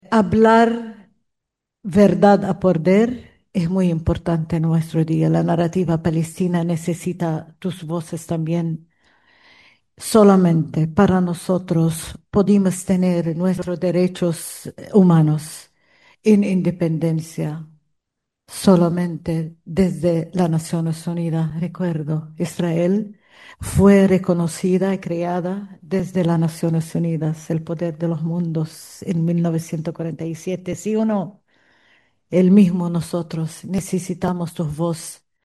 La embajadora de Palestina en Chile, Vera Baboun, fue la expositora invitada a la charla “Palestina hoy: una mirada desde los Derechos Humanos”, organizada por el Equipo de Litigación Internacional en Derechos Humanos de la Universidad de Concepción.